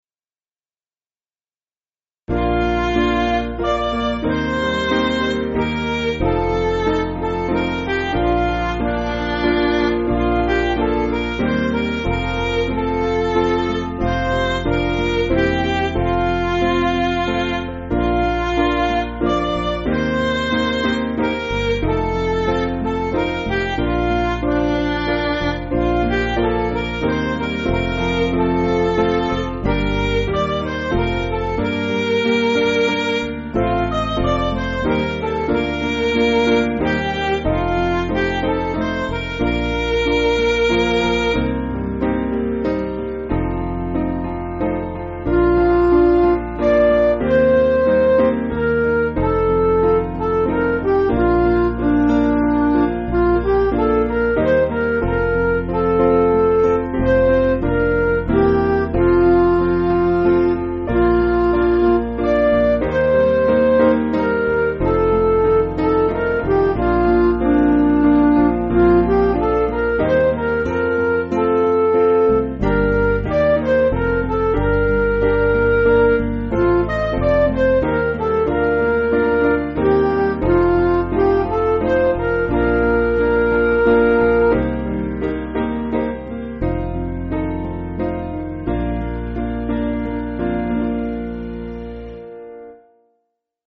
Piano & Instrumental
(CM)   2/Bb